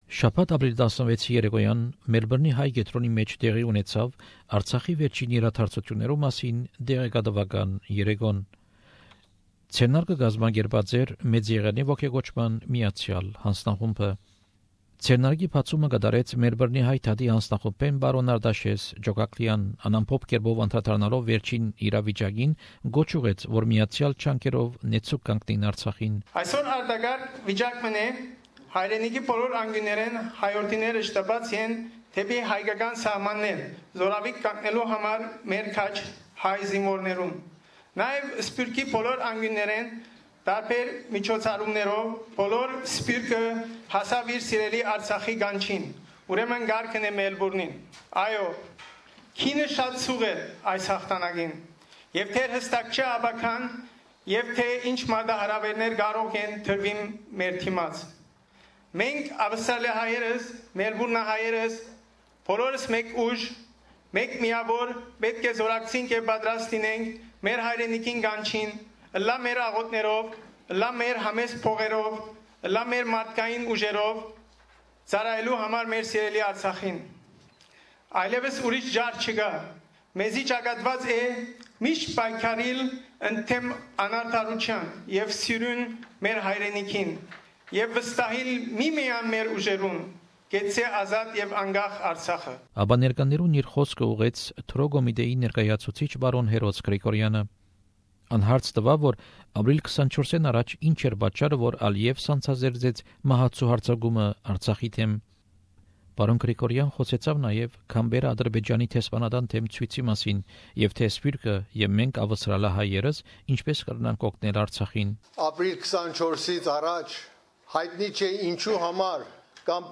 An information evening was organised at the Armenian Centre in Melbourne, in solidarity with the population of Artsakh who came under attack two weeks ago.